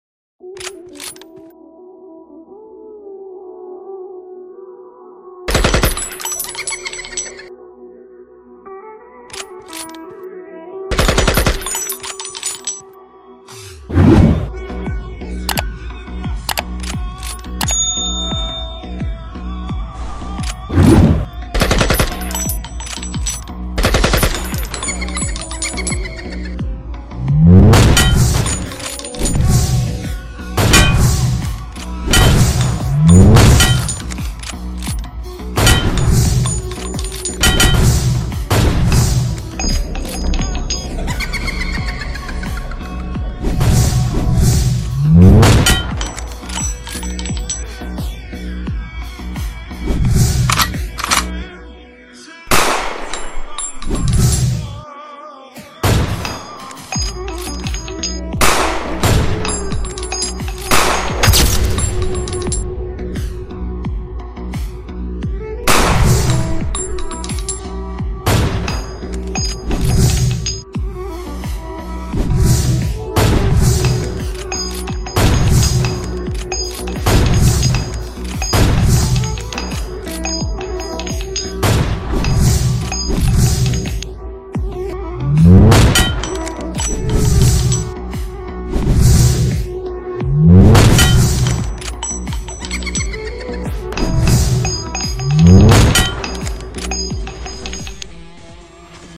Free Fire Gameplay with PubG sound effects free download